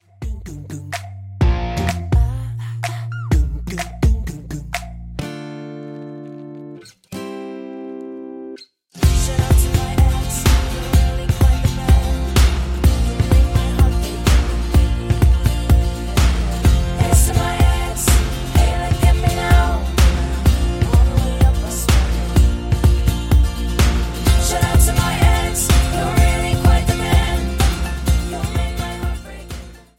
MPEG 1 Layer 3 (Stereo)
Backing track Karaoke
Pop, 2010s